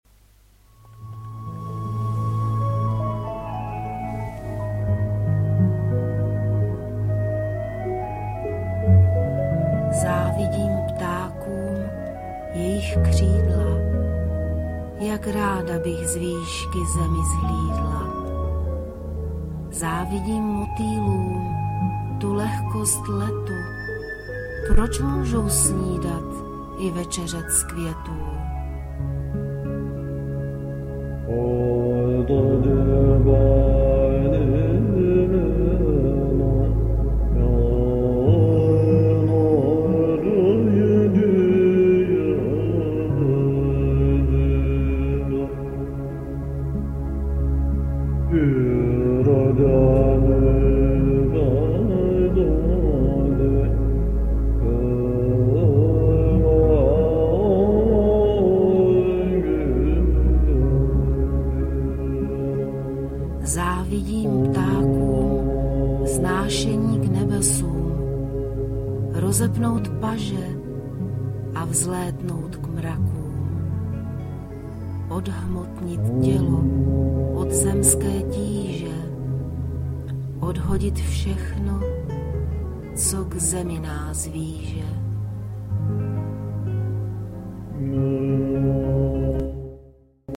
Z tohoto důvodu jsem pro Vás vytvořila relaxační CD.
Ukázka relaxace vznášení.mp3